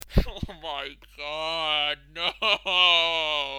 Crying.